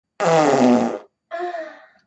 girl-farting-cute